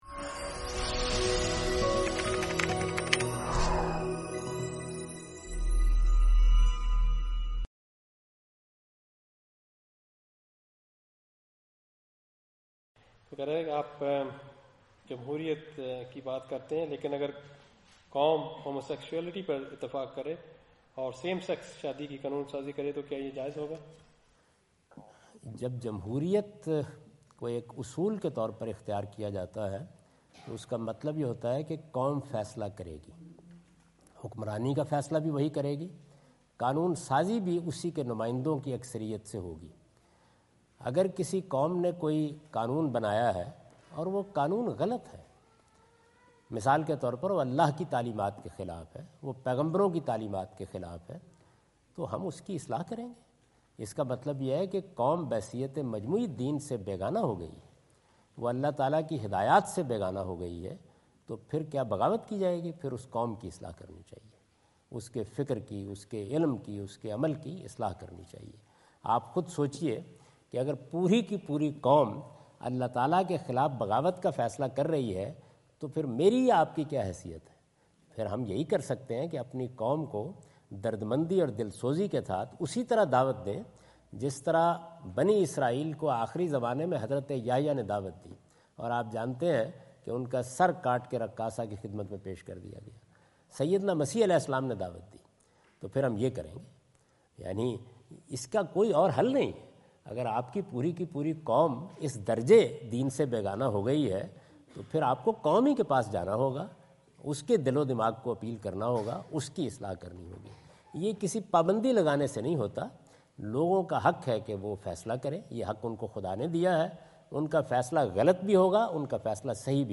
Javed Ahmad Ghamidi answer the question about "How to React to Un-Islamic Laws in an Islamic State?" during his visit in Canberra Australia on 03rd October 2015.
جاوید احمد غامدی اپنے دورہ آسٹریلیا کے دوران کینبرا میں "مسلم ریاست میں غیر اسلامی قوانین پر درست ردعمل؟" سے متعلق ایک سوال کا جواب دے رہے ہیں۔